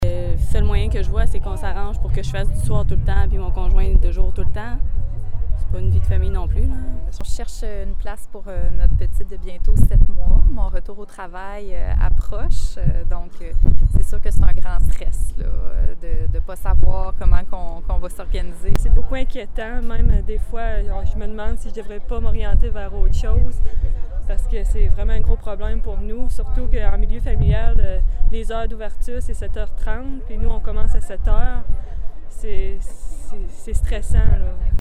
Plusieurs femmes rencontrées ce matin, qui travaillent dans le domaine de la santé, sont inquiètent de cette situation :